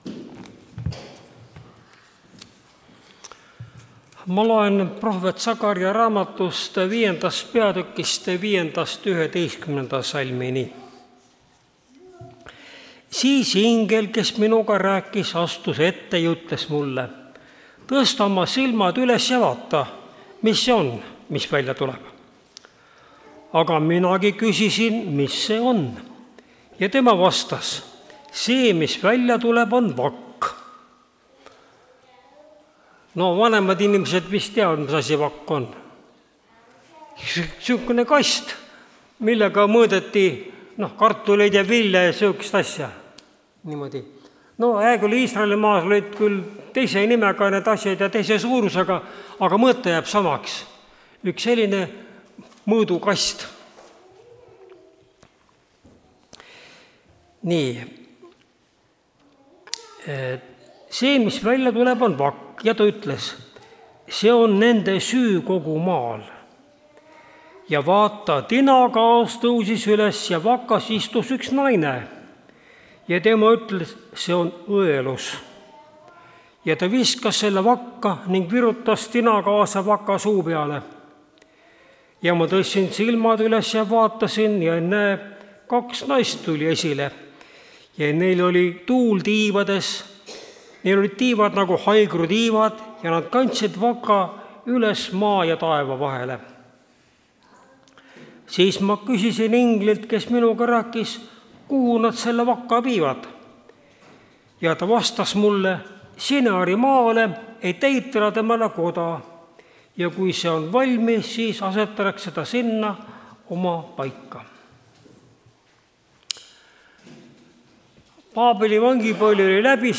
Tartu adventkoguduse 11.01.2025 hommikuse teenistuse jutluse helisalvestis.